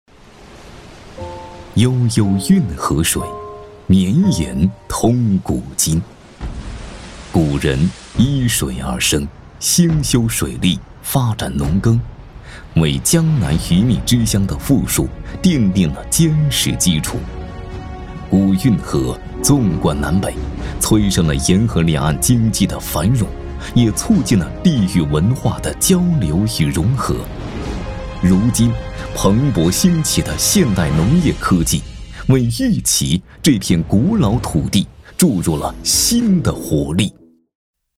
男国语114